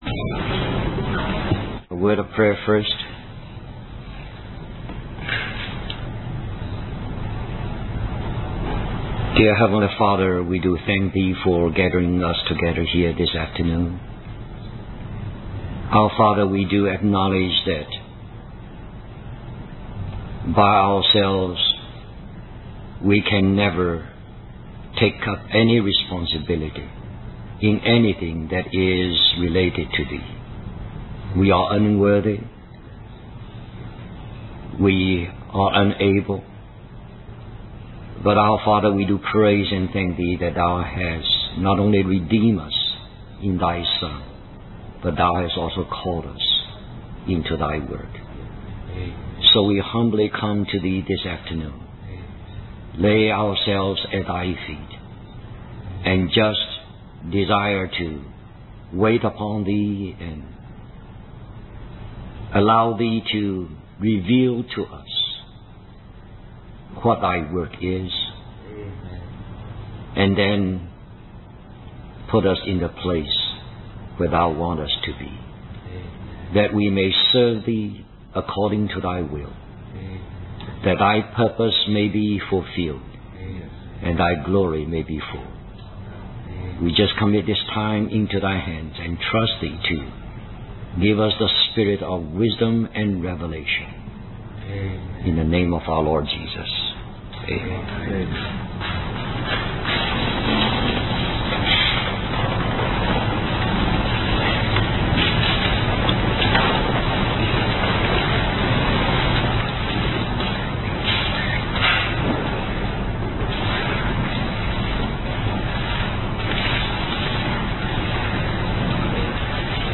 The sermon concludes with an invitation for the audience to ask any questions they may have about responsibility in God's work.